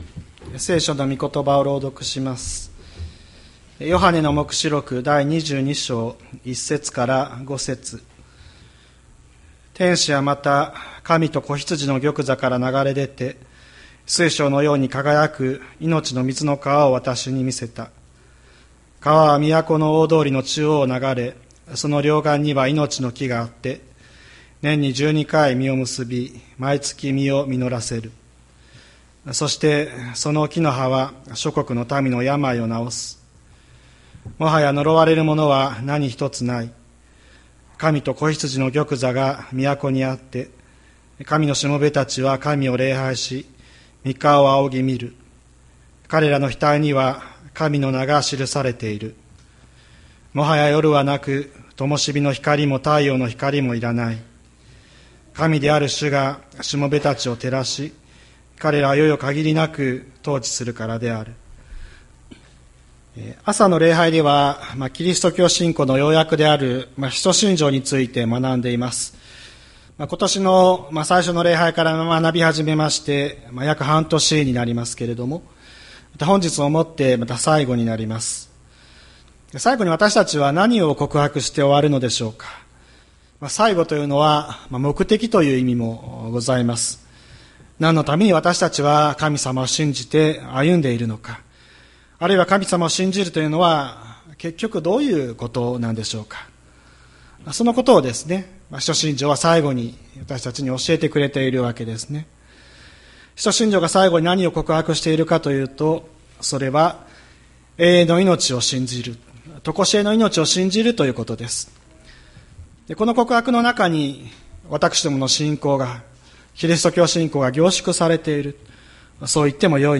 千里山教会 2024年07月14日の礼拝メッセージ。